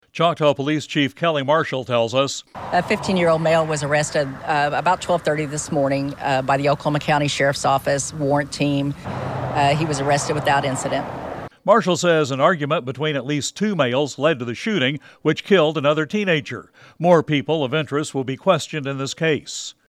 commentary from Choctaw Police Chief Kelly Marshall